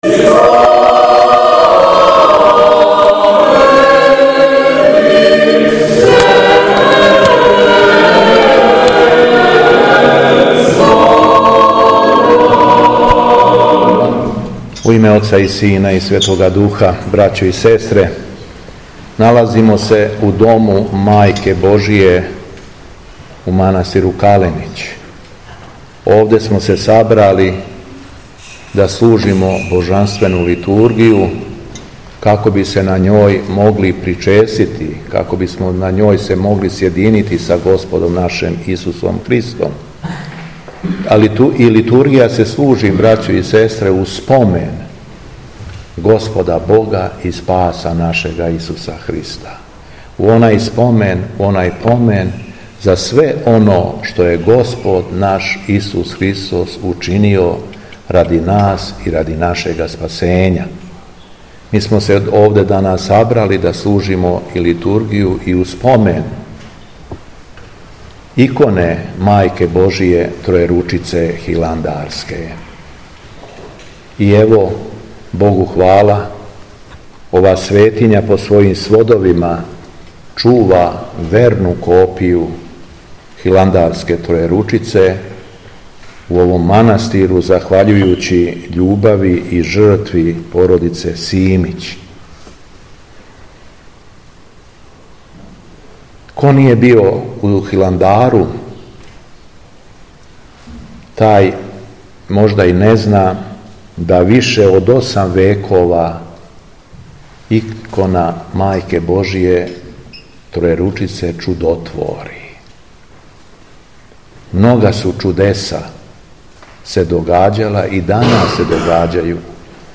У четвртак 25. јула 2024. године, када наша Света Црква прославља Чудотворну икону Пресвете Богородице Тројеручице, његово Високопреосвештенство Митрополит шумадијски Господин Јован, служио је Свету Архијерејску Литургију у манастиру Каленић у ком се чува чудотворна копија ове иконе.
Беседа Његовог Високопреосвештенства Митрополита шумадијског г. Јована